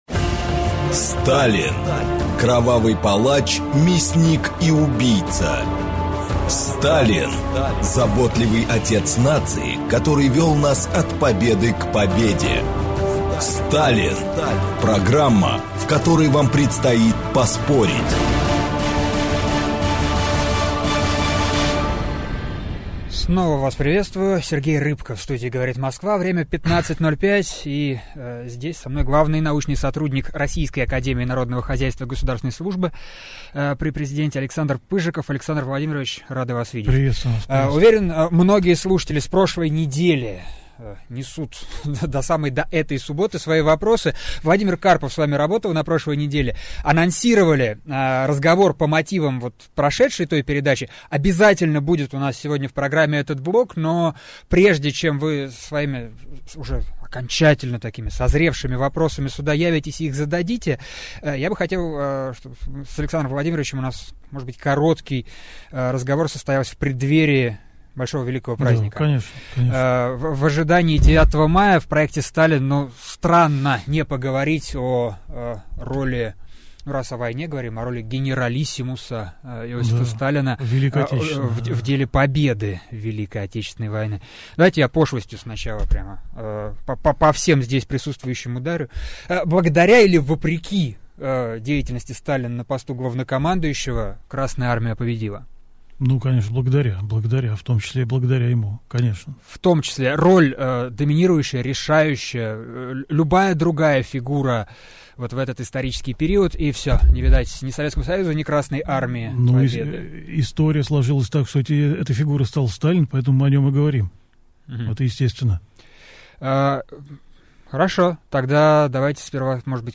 Аудиокнига Роль Сталина в победе | Библиотека аудиокниг